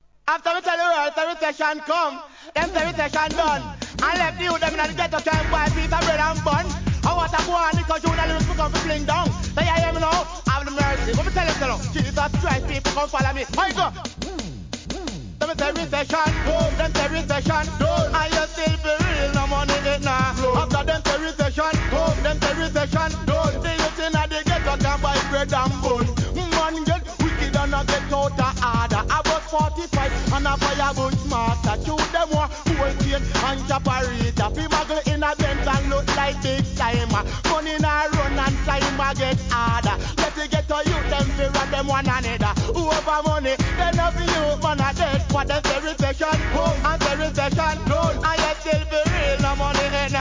REGGAE
ラガHIP HOPなREMIX収録!!